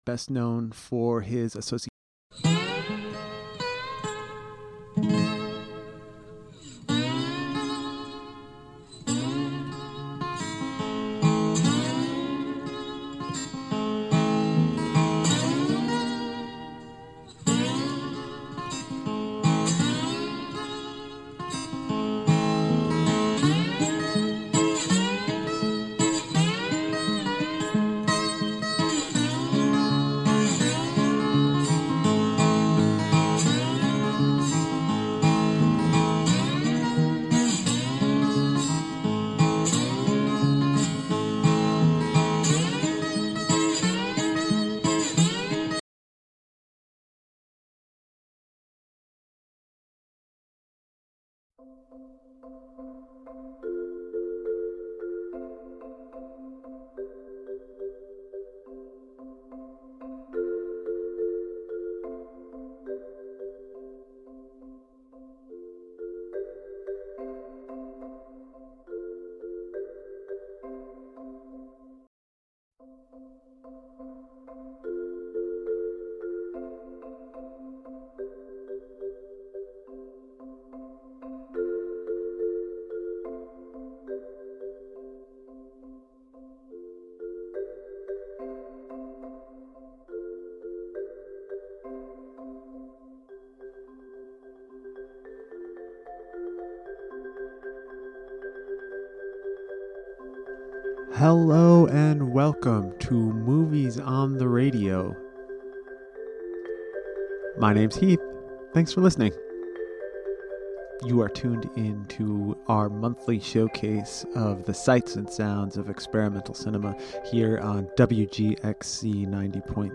"Movies on the Radio" is a showcase for sounds synchronized to experimental film and video work by artists from Columbia and Greene counties and across the world. Listen for live audio accompaniment to works screening at the FILMont Media Project's outdoor Sidewalk Cinema, located at The Purpose Makerspace, 100 Main Street in Philmont, New York. Listen for soundtracks of old jazz, analog noise, psychogeographic musings, and more along with interviews with media artists and makers from Philmont, and beyond.